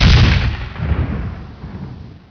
explode2.wav